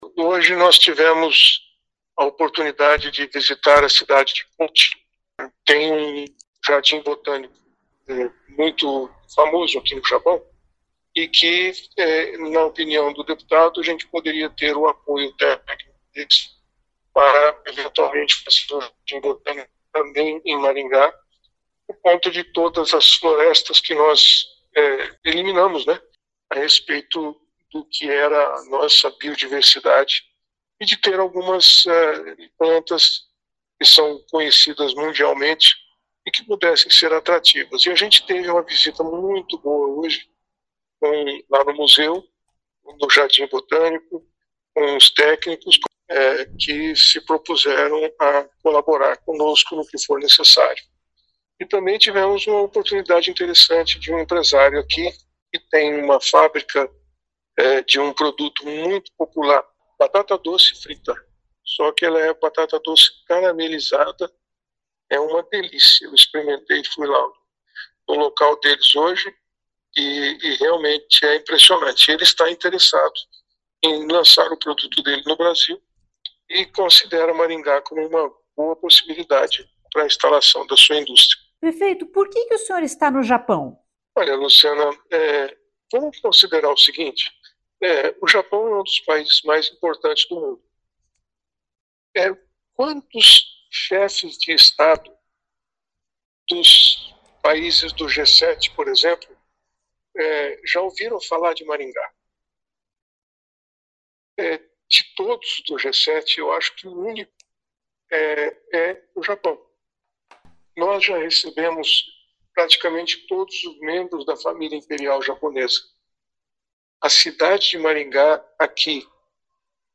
O prefeito explicou por que está no Japão, mesmo com os problemas internos que Maringá enfrenta.